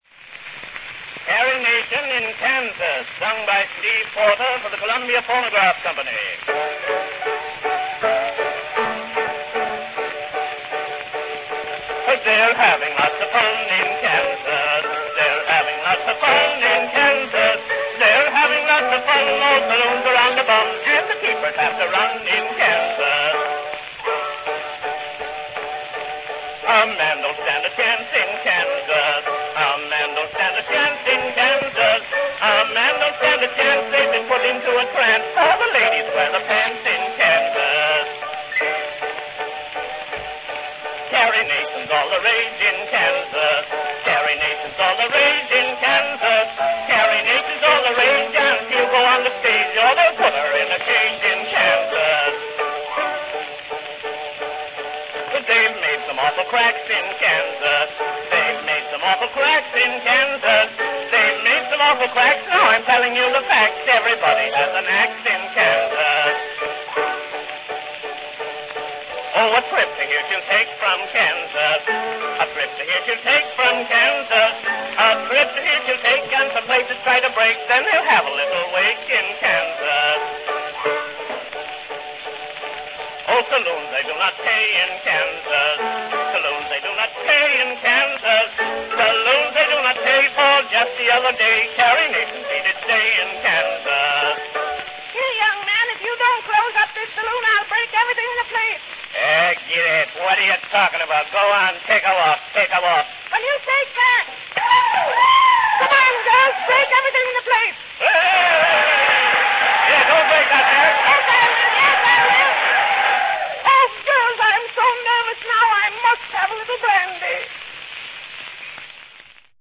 Listen to a complete two-minute wax cylinder recording -- A new cylinder every month.